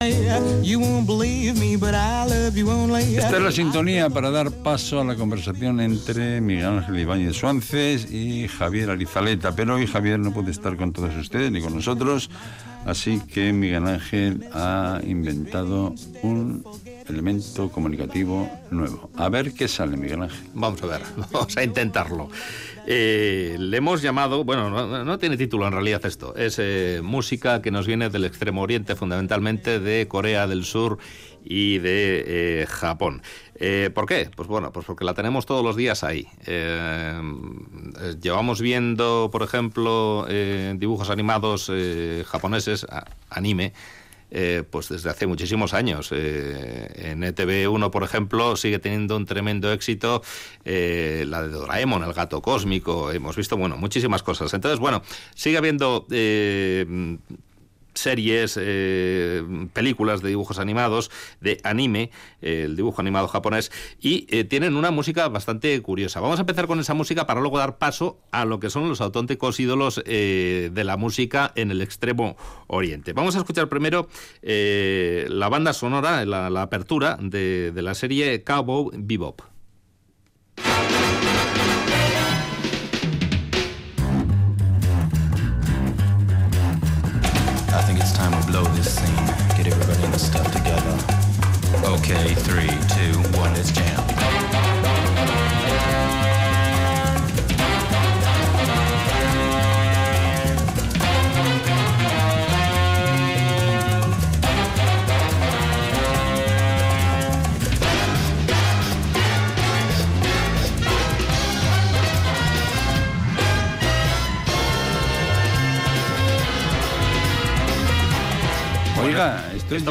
Audio: Reportaje musical: La música que nos llega del lejano oriente